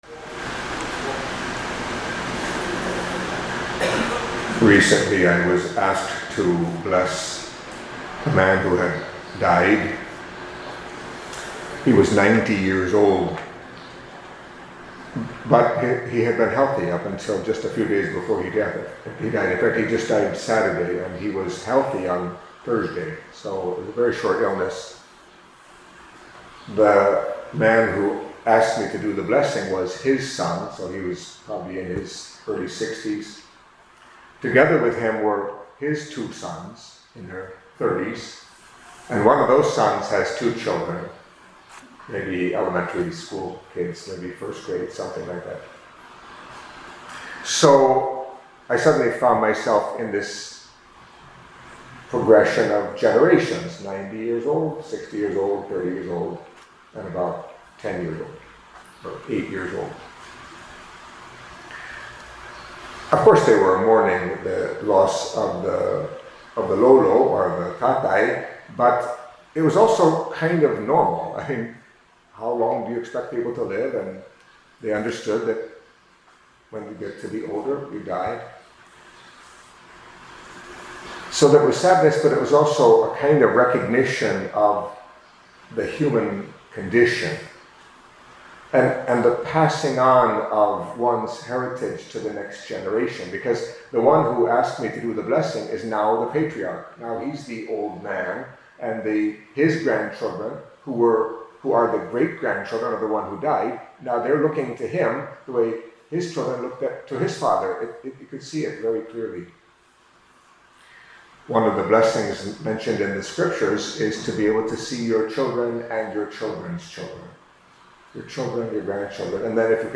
Catholic Mass homily for Thursday of the Fourth Week in Ordinary Time